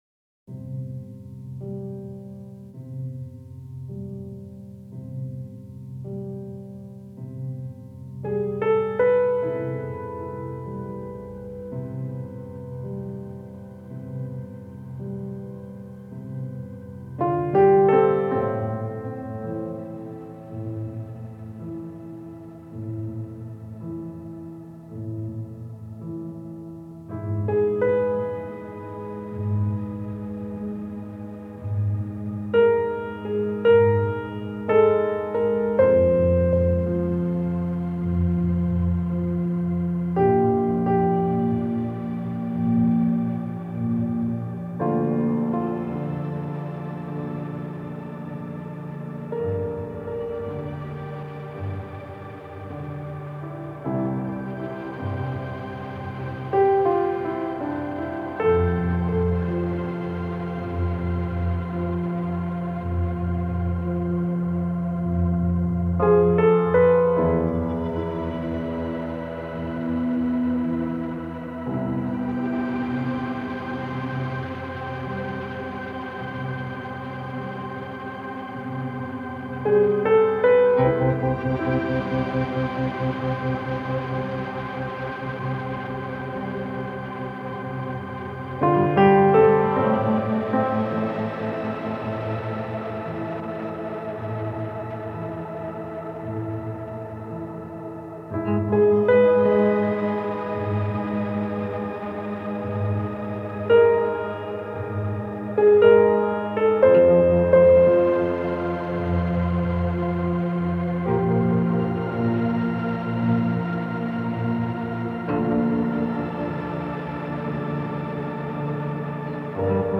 Piano uneasiness joint by mysterious warm synths.